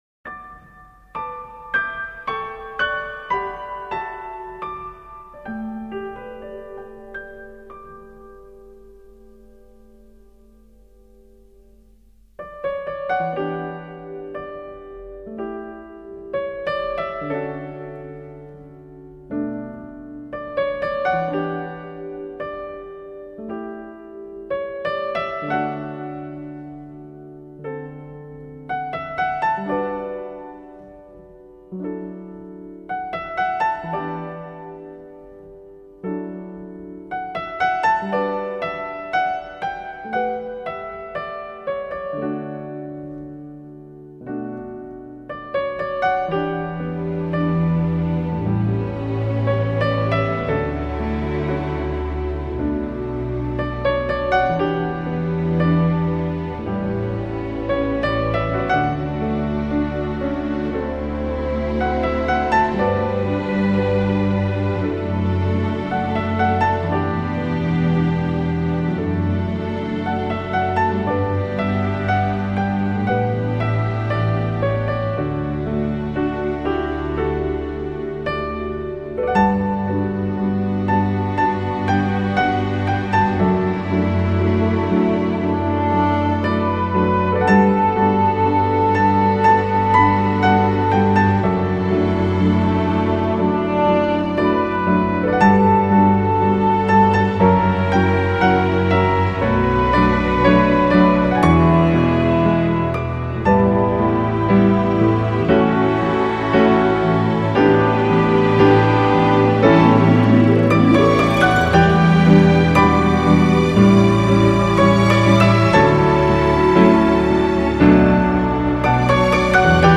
专辑歌手：原声大碟